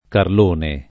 karl1ne] pers. m. — accr. di Carlo: es. il San Carlone, la grande statua di san Carlo Borromeo presso Arona — sim. i top. il Carlone (Tosc.), la Carlona (Piem.) e i cogn. Carlone, Carloni